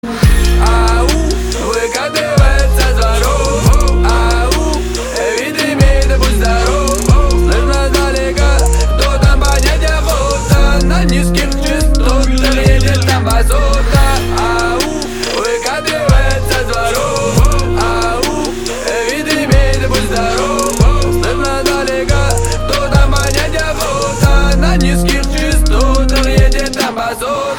• Качество: 320, Stereo
русский рэп
Trap
басы
качающие